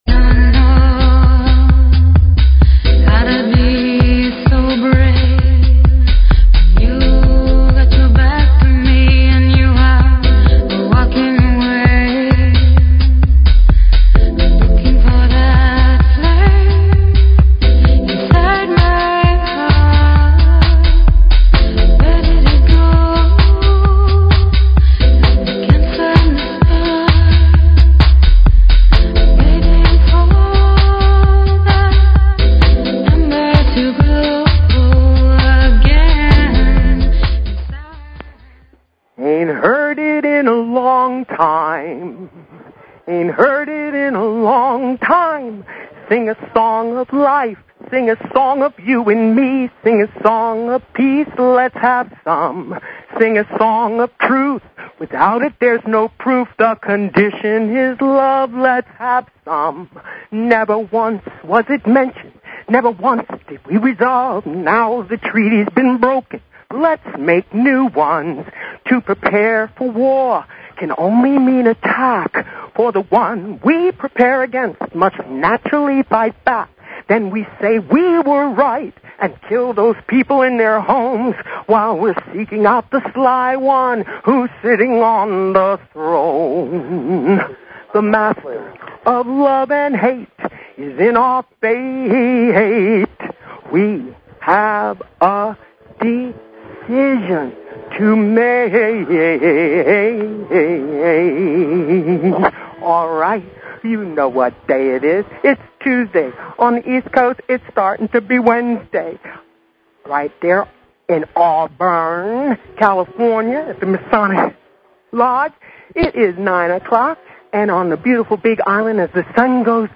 BROADCASTING ON LOCATION FROM A CALIFORNIA LOVE CODE LECTURE